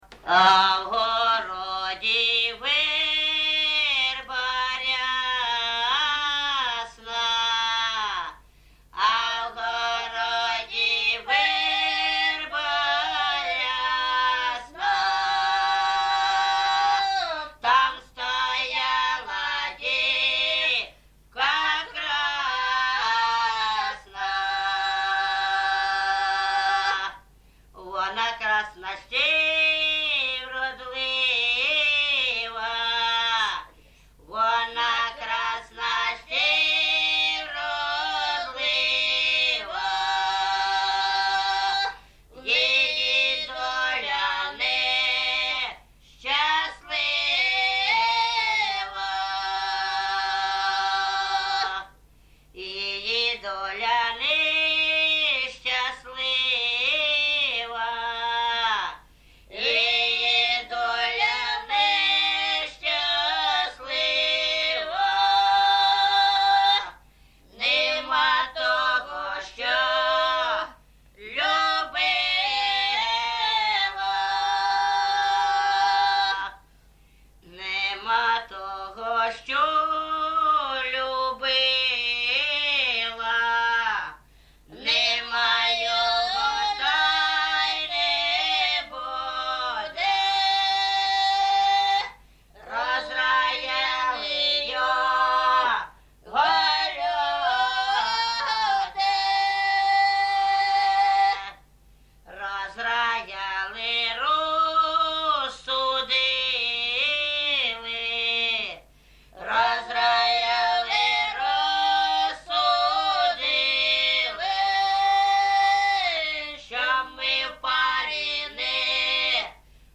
Місце записус. Гарбузівка, Сумський район, Сумська обл., Україна, Слобожанщина